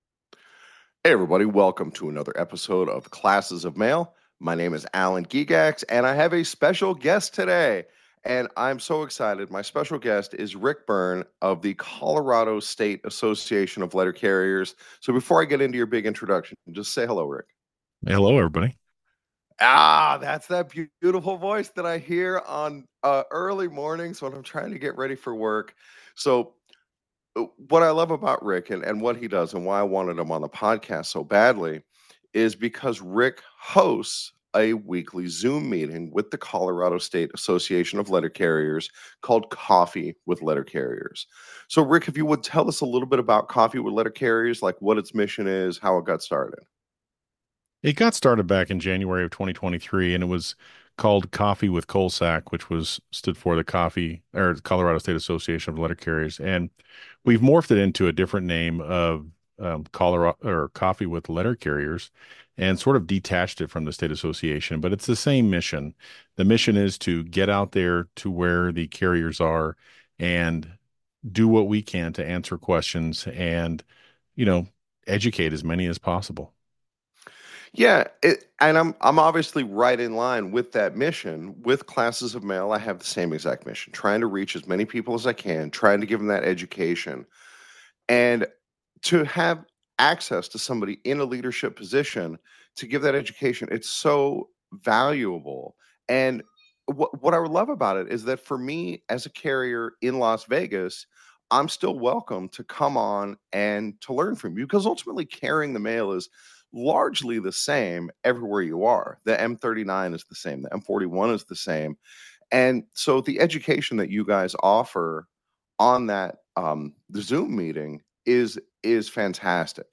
We also talk about what a State Association does. This episode probably has the best sound quality of any I've recorded.